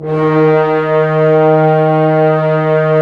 Index of /90_sSampleCDs/Roland - Brass, Strings, Hits and Combos/ORC_Orc.Unison f/ORC_Orc.Unison f